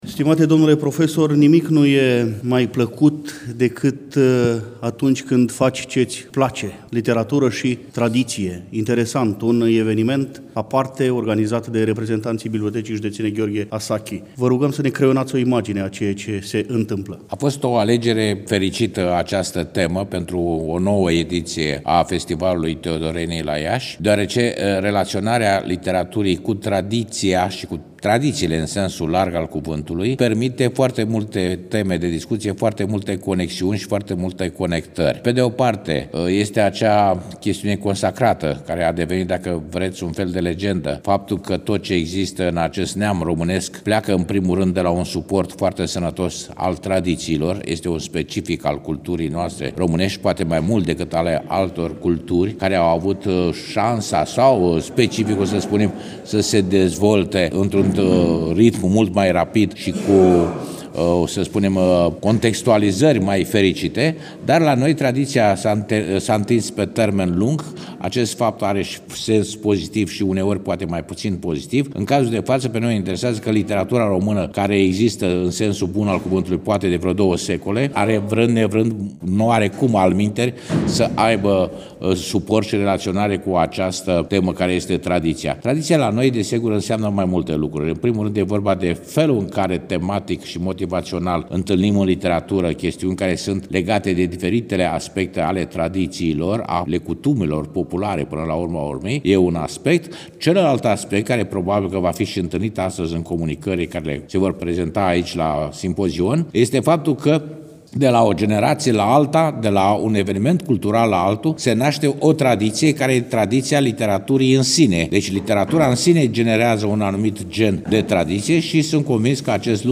Dragi prieteni, după cum bine știți, în ediția de astăzi a emisiunii Tradiții, relatăm de la ediția a X-a a Festivalului „Teodorenii”, eveniment desfășurat, la Iași, în perioada 10 – 12 decembrie 2024.
interviu în care ne povestește despre relaționarea literaturii cu tradiția